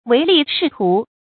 注音：ㄨㄟˊ ㄌㄧˋ ㄕㄧˋ ㄊㄨˊ
唯利是圖的讀法